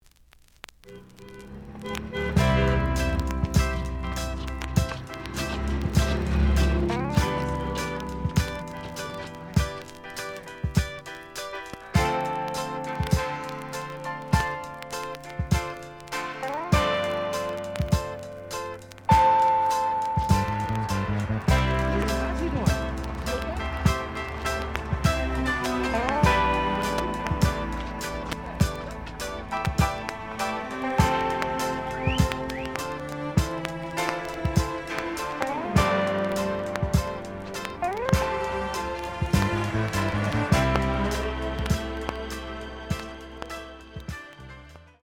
The audio sample is recorded from the actual item.
●Genre: Disco
Some click noise on both sides due to scratches.)